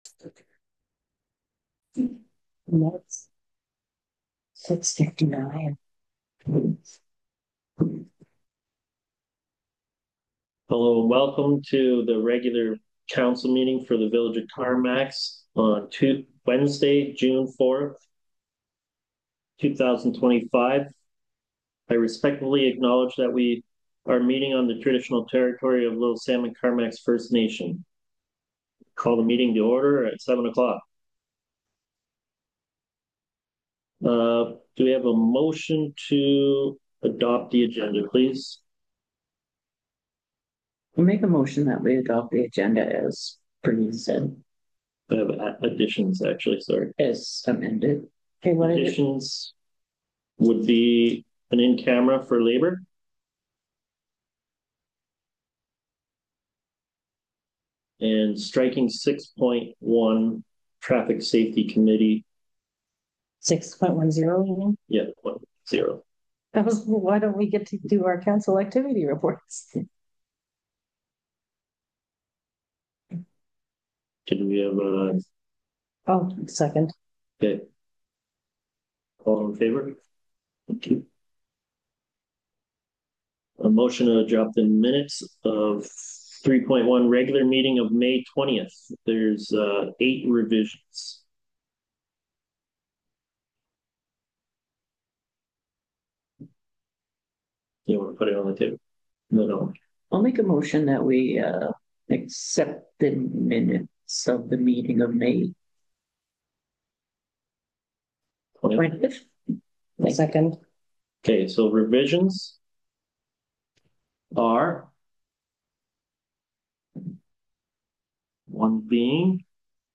25-11 Council Meeting